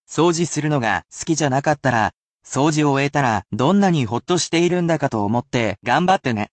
However, it will be at normal speed which may be difficult for beginners, but should assist you in getting used to the speed of the language, but this will act as useful listening practise.
[casual speech]